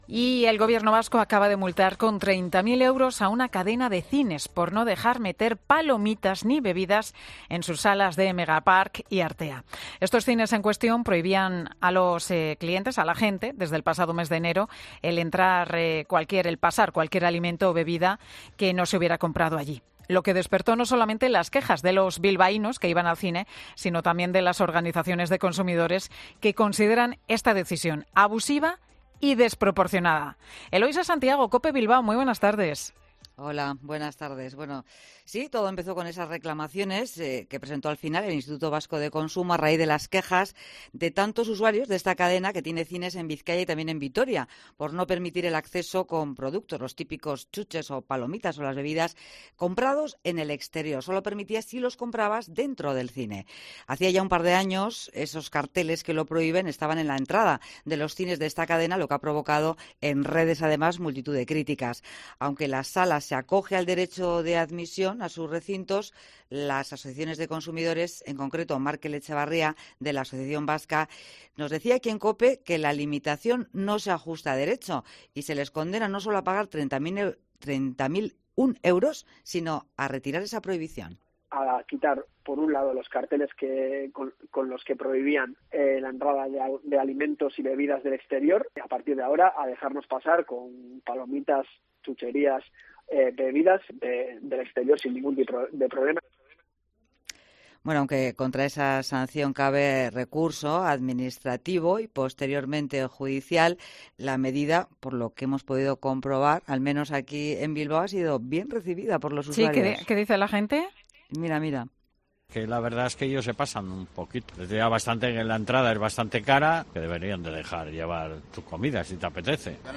La reacción de los bilbaínos a la multa impuesta a Yelmo Cines por prohibirles entrar con comida